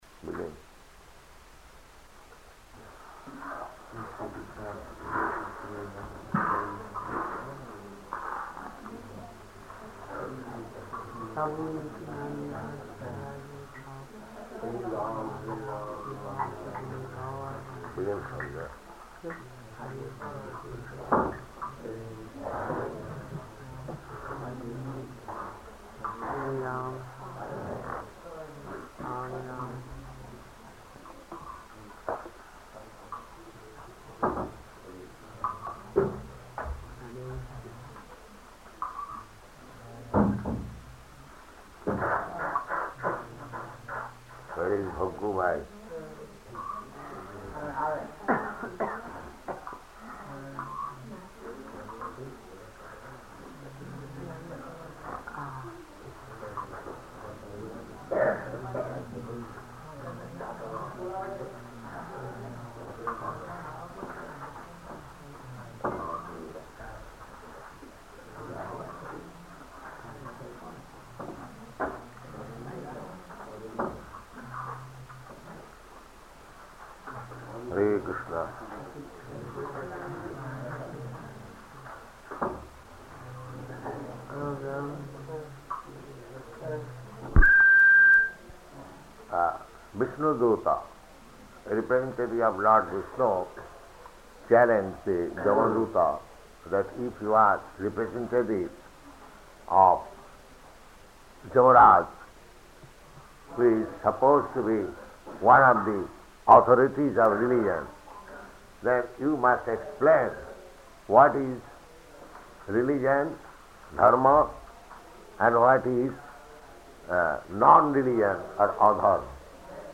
Śrīmad-Bhāgavatam 6.1.39–40 --:-- --:-- Type: Srimad-Bhagavatam Dated: December 21st 1970 Location: Surat Audio file: 701221SB-SURAT.mp3 Prabhupāda: Begin.